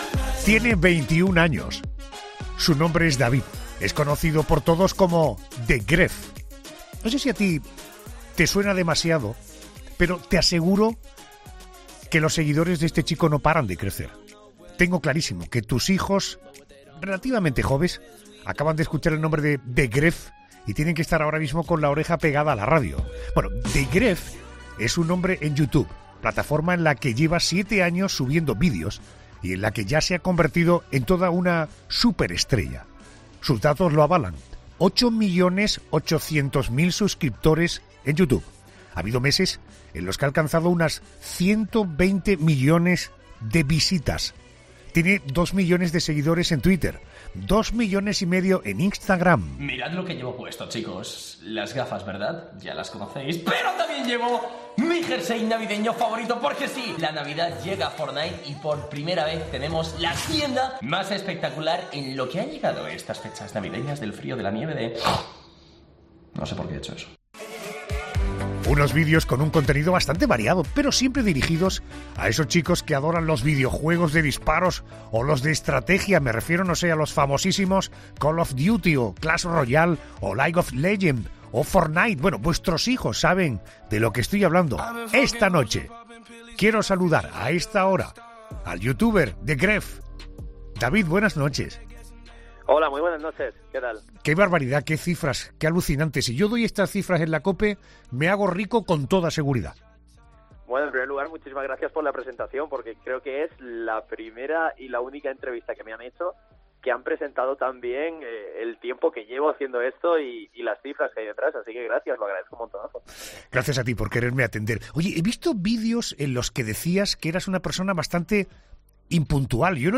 El youtuber 'TheGref' en 'La Noche'